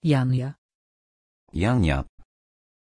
Pronunciation of Janja
pronunciation-janja-pl.mp3